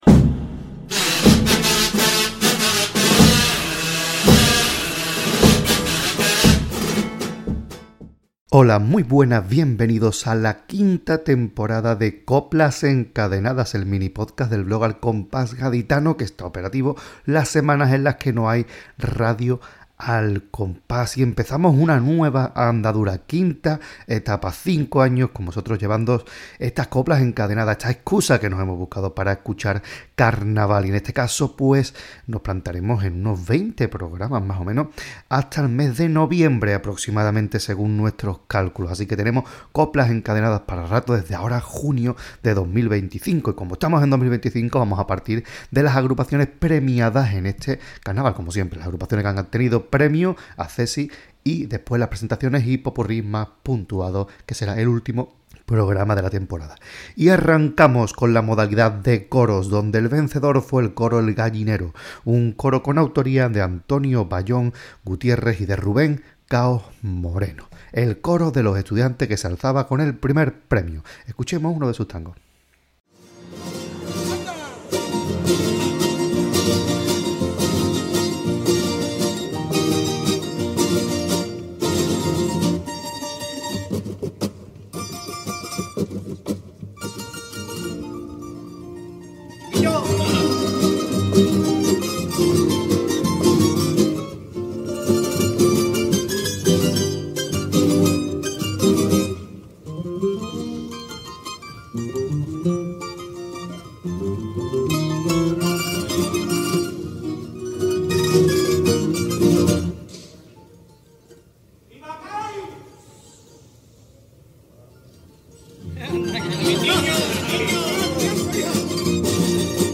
coros
Tango
Pasodoble